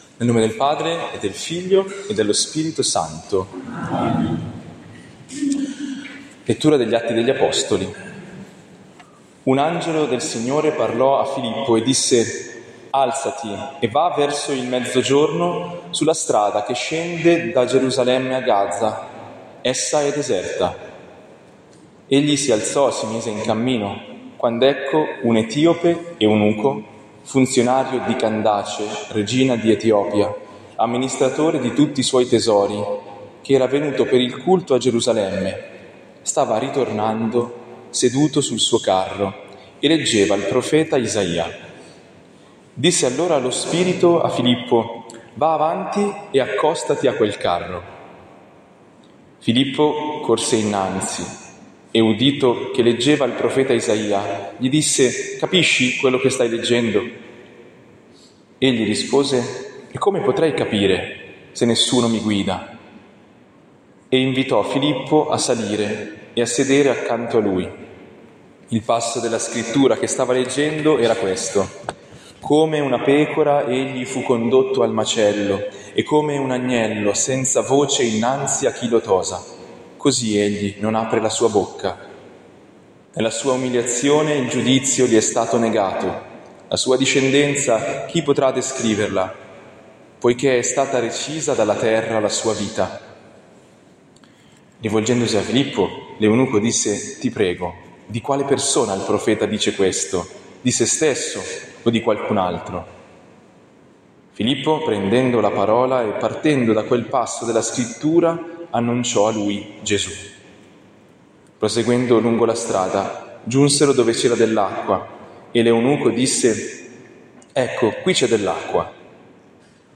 Libro di padre J. Mallon, DIVINO RINNOVAMENTO Riflessione su Evangelii Gaudium Riflessione sul Sinodo sulla Sinodalità 2021-2024 Ritiro di Quaresima 2026 🔊 ascolta la catechesi
catechesi-ritiro-quaresima-2026-CP.mp3